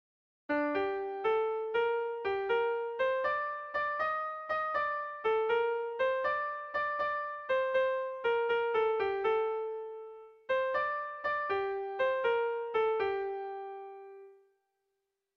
Erromantzea
Zuberoa < Euskal Herria
Lauko berdina, 2 puntuz eta 8 silabaz (hg) / Bi puntuko berdina, 16 silabaz (ip)
ABDE